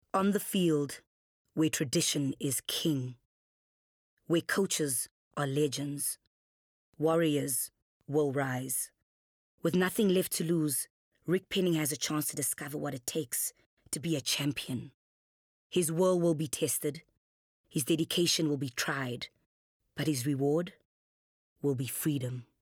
Female
South African English (Native) , Afrikaans
Confident
Voice reels